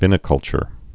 (vĭnĭ-kŭlchər, vīnĭ-)